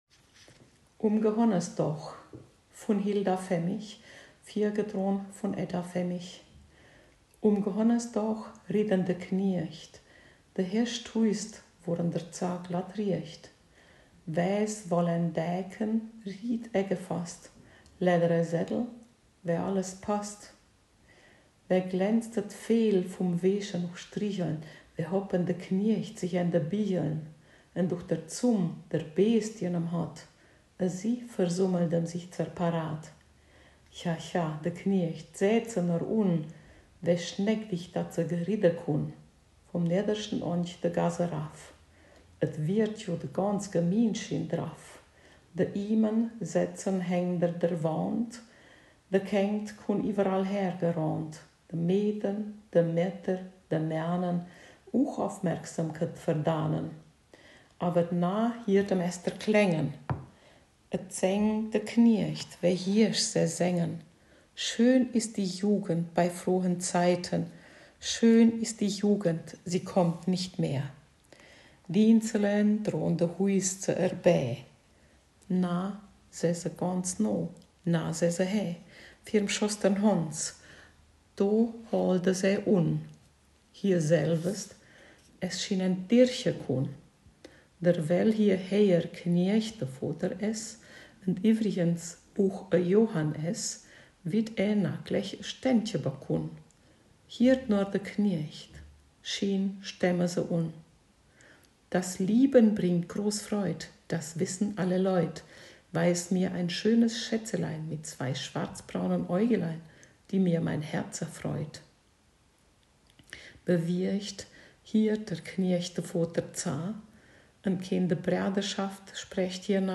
Ortsmundart: Hermannstadt